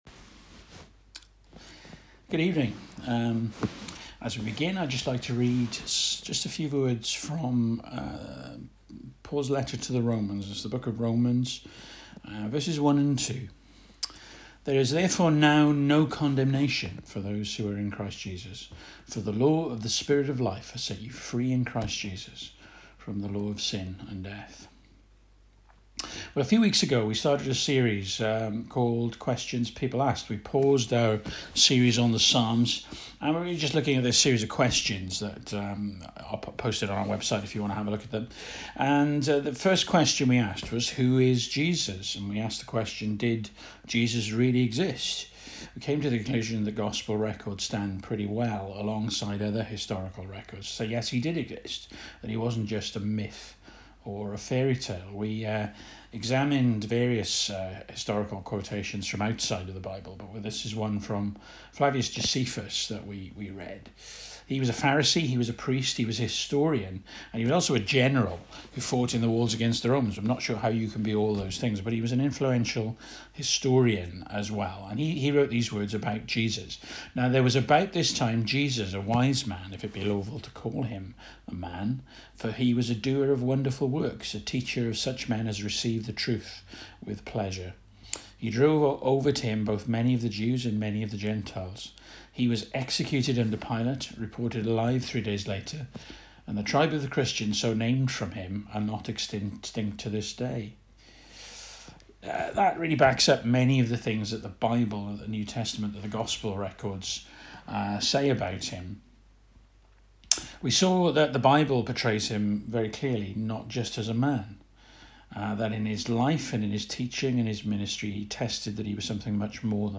Romans 8:1-2 Service Type: Evening Bible Text